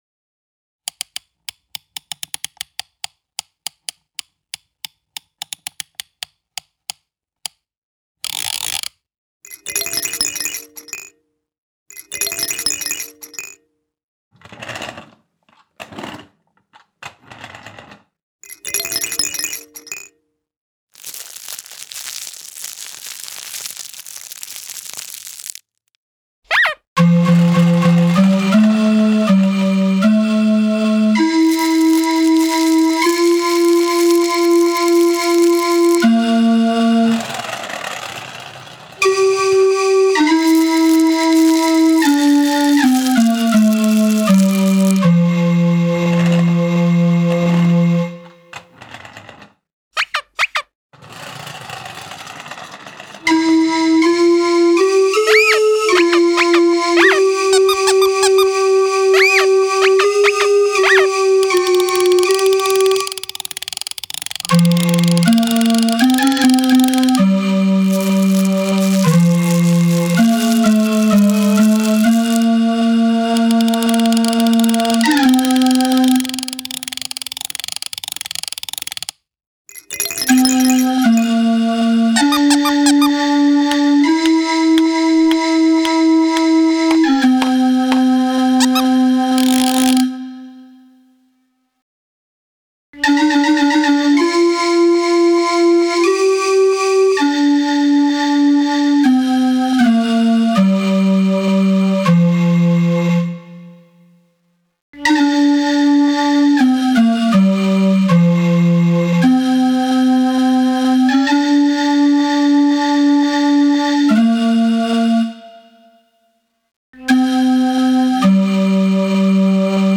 AI generated auditory artwork